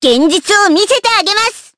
Hanus-Vox_Skill6_jp.wav